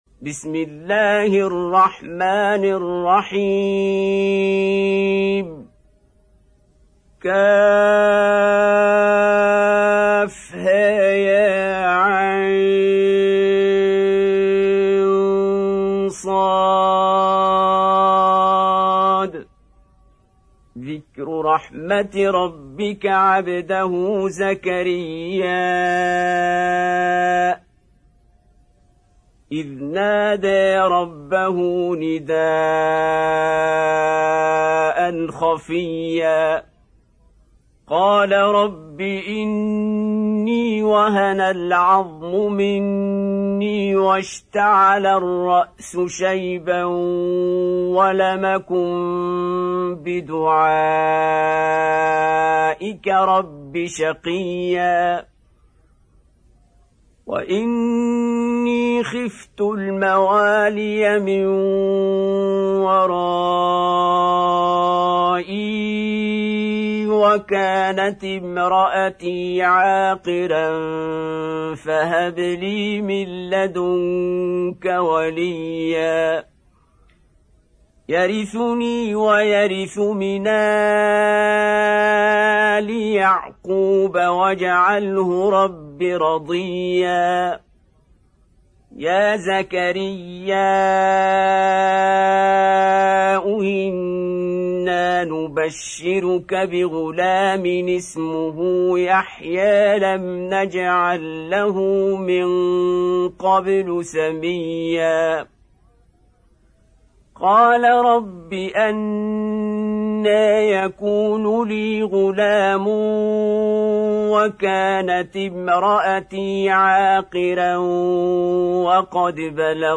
Surah Maryam Beautiful Recitation MP3 Download By Qari Abdul Basit in best audio quality.